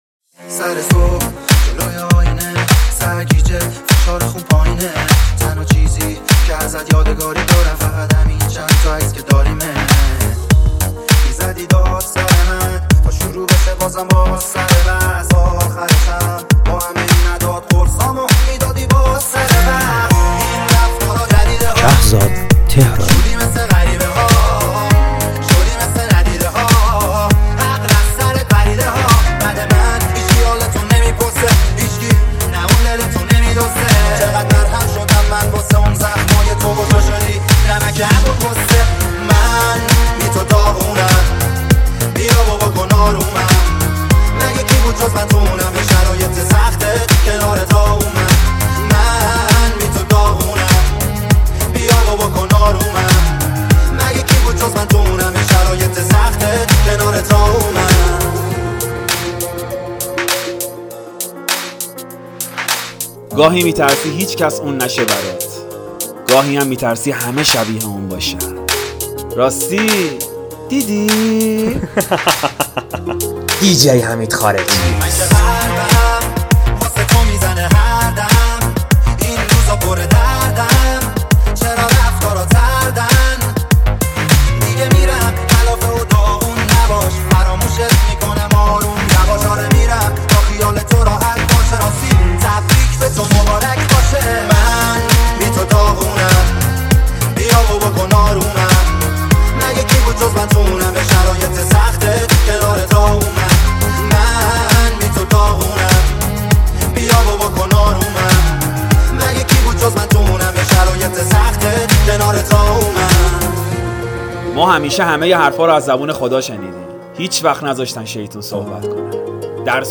موسیقی پاپ